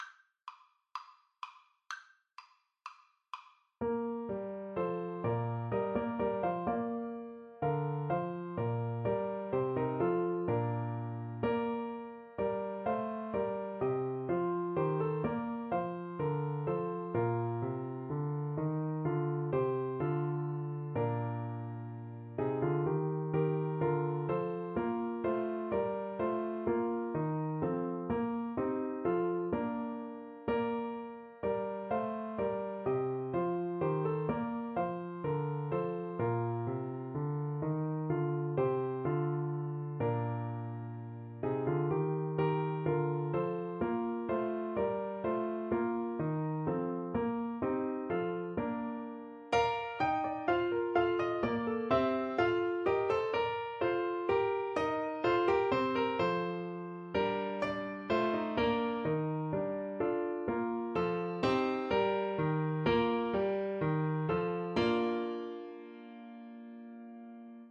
Flute version
Christmas
Allegro = 126 (View more music marked Allegro)
4/4 (View more 4/4 Music)
Flute  (View more Easy Flute Music)